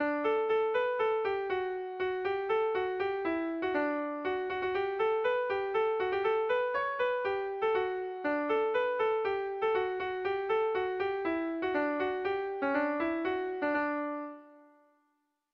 Air de bertsos - Voir fiche   Pour savoir plus sur cette section
ABDAB1B2